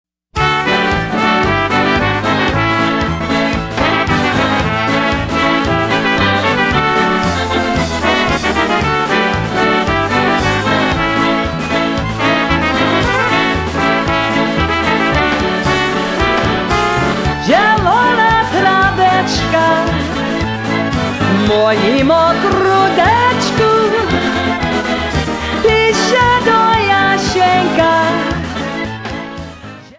Polkas, Waltzes and Obereks